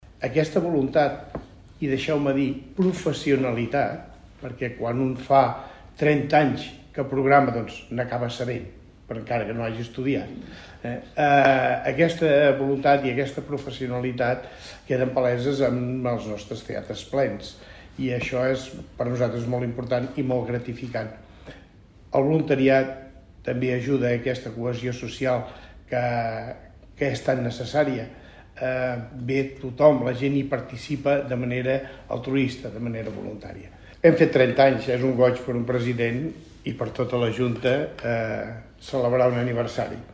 Roda de Premsa presentació 14è Tast d'Espectacles Familiars